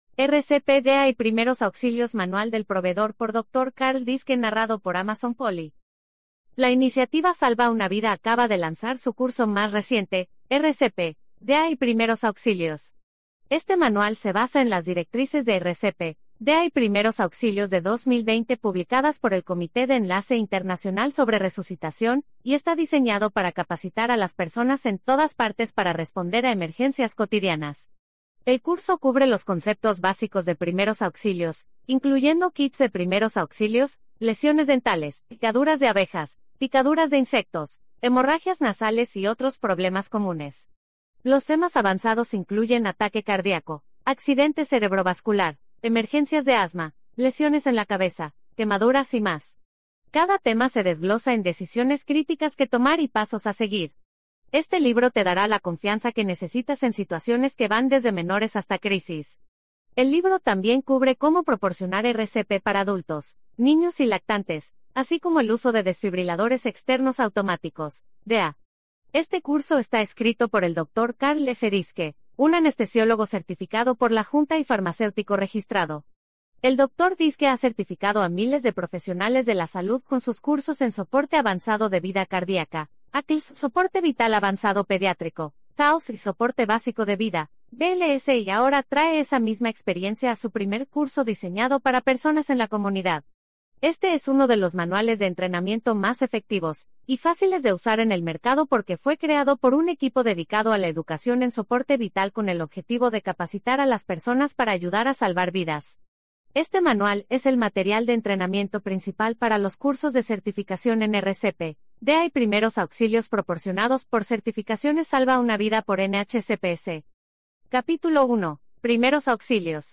Free Audiobooks Download | Advanced Medical Certification
CPR_Spanish_Audiobook.mp3